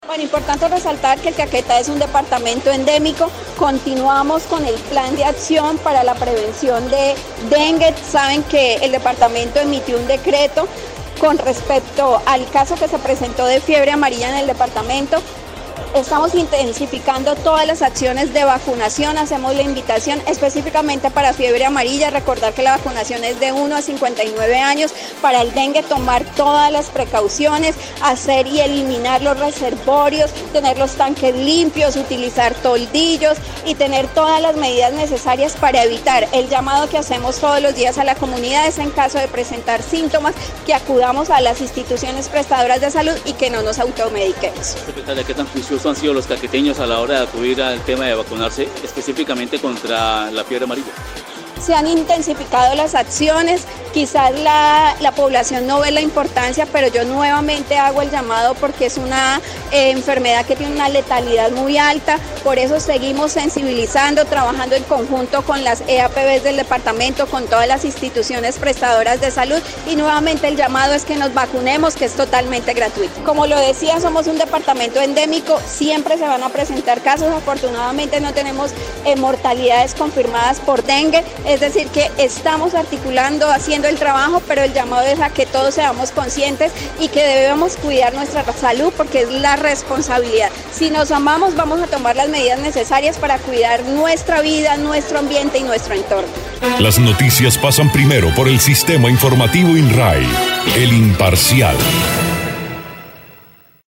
Así lo dio a conocer la secretaria de salud departamental, Mallerly González Arias, quien hizo un llamado a la ciudadanía para que, en caso de presentar síntomas de dichas enfermedades, esta no se automedique y acudan de inmediato a los centros asistenciales.